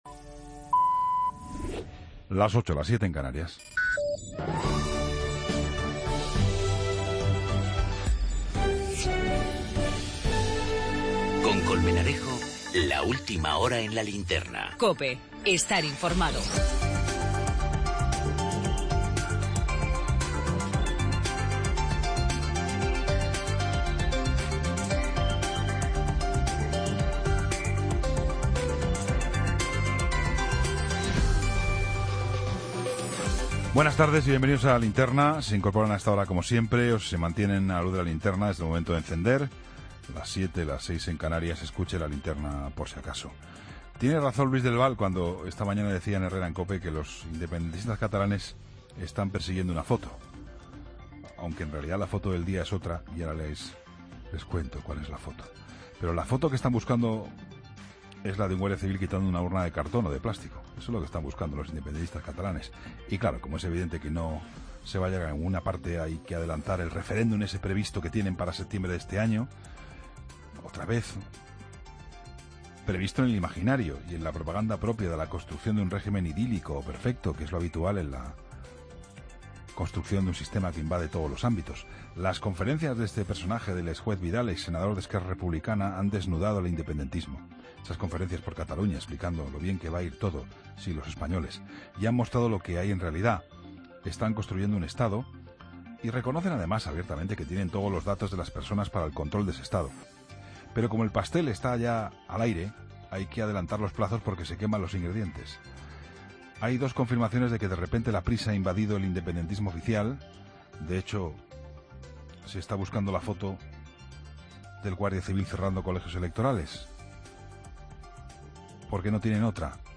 El análisis de actualidad